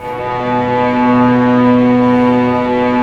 Index of /90_sSampleCDs/Roland LCDP08 Symphony Orchestra/STR_Vcs Bow FX/STR_Vcs Sul Pont